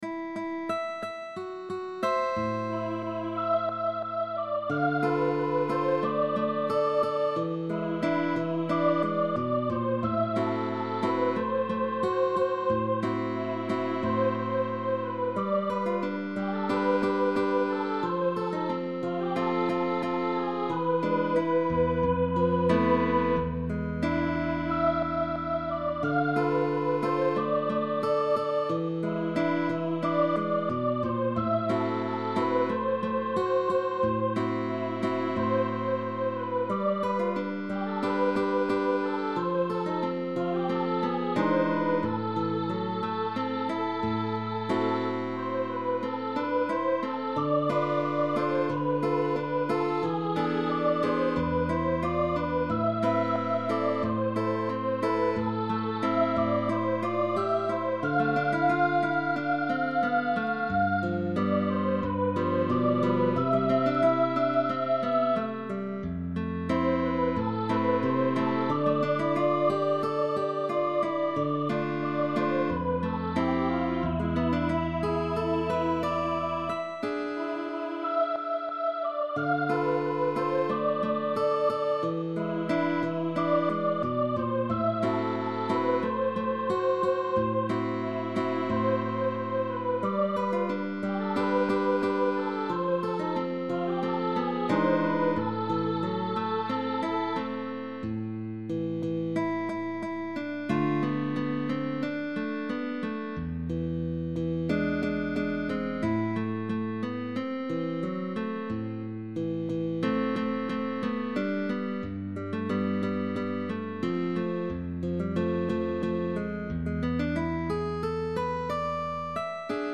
SOPRANO & GUITAR Bolero (Mexican melodic song).